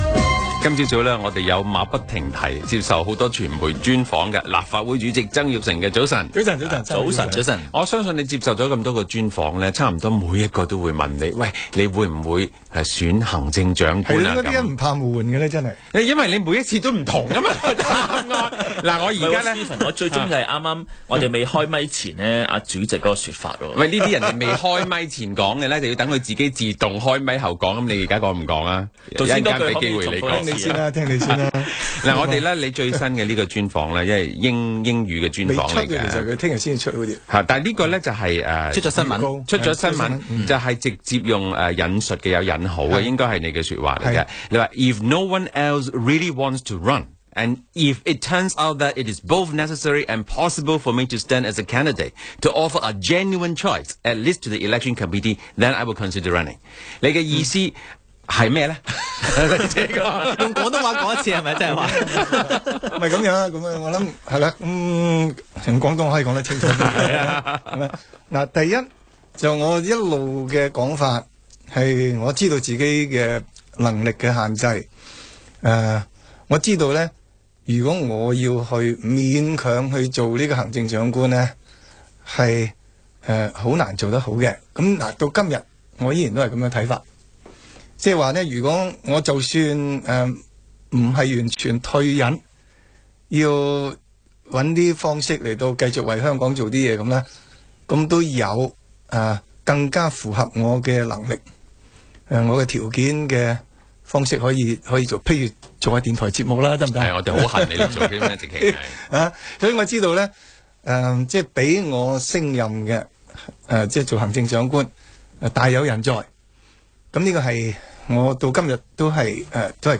商業電台《在晴朗的一天出發》訪問